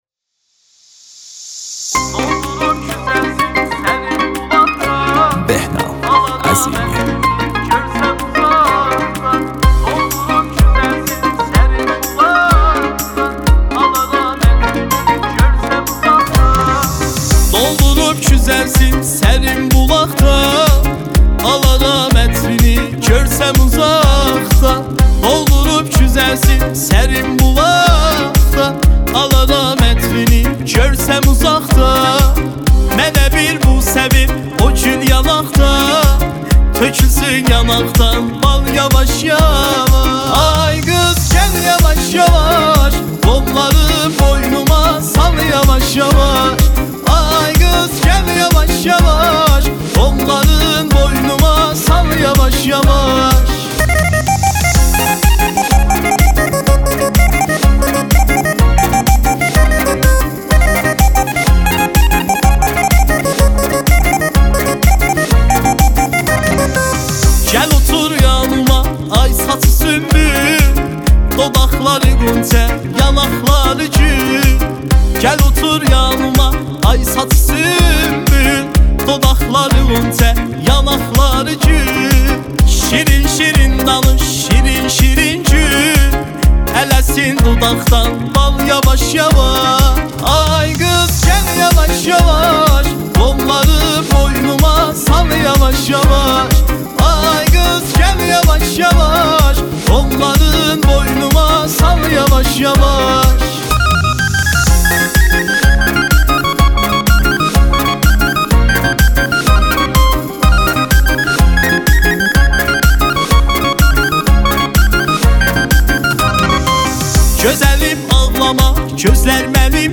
دانلود آهنگ ترکی جدید